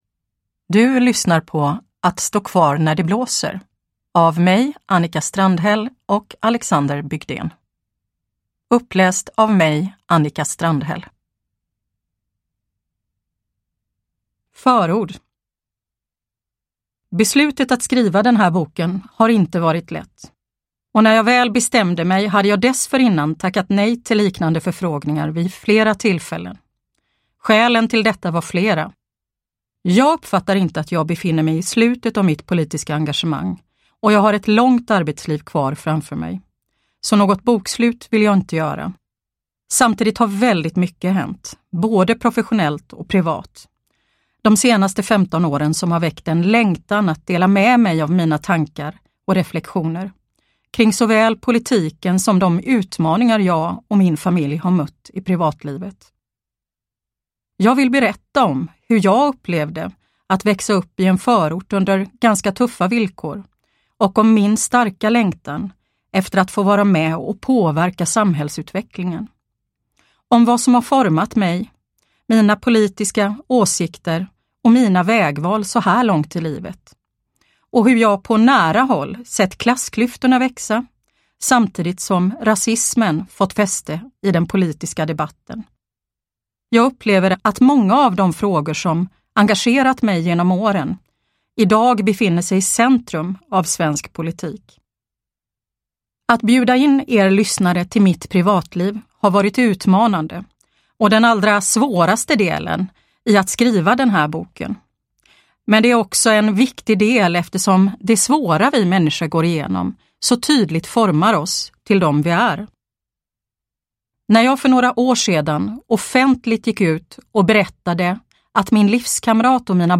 Att stå kvar när det blåser – Ljudbok
Uppläsare: Annika Strandhäll